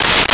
Tear Paper 05
Tear Paper 05.wav